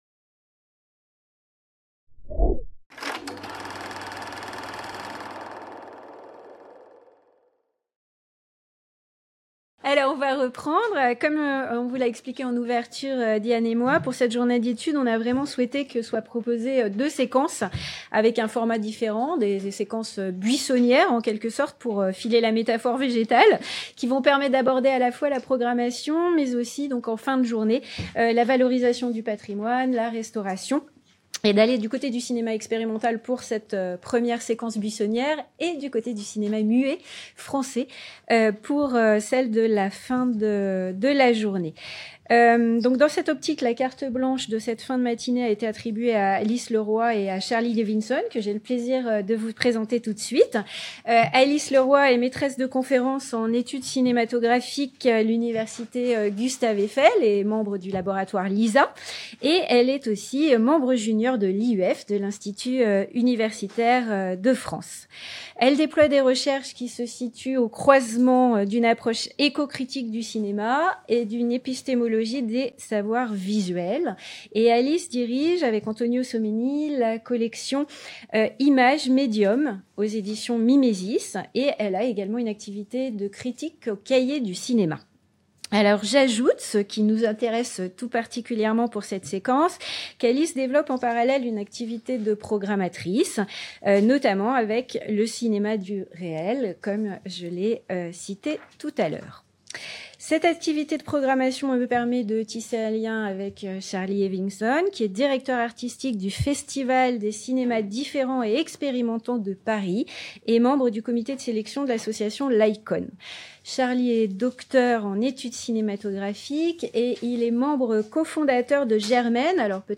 JOURNÉE D'ÉTUDE - ÉCOPOÉTIQUE DE LA VIE PÉRIURBAINE AU CINÉMA - DEUXIÈME PARTIE | Canal U